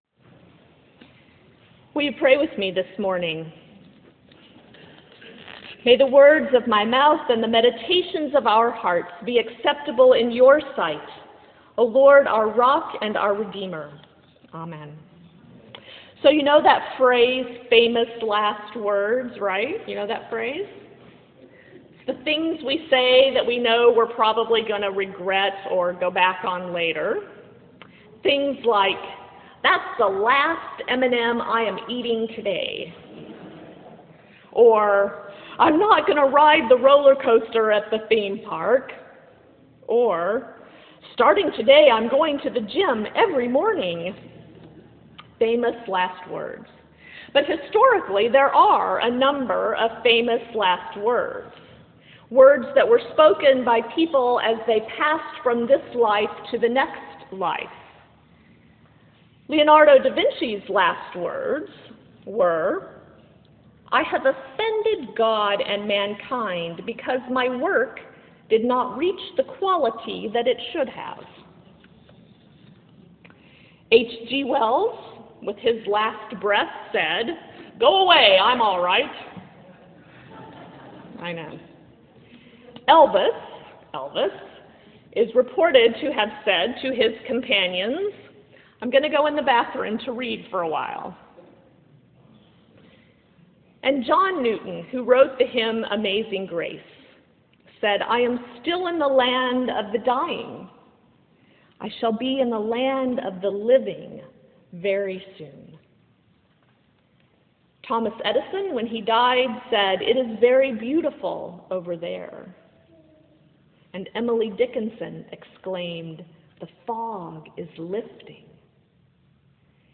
Luther Memorial Church Seattle, WA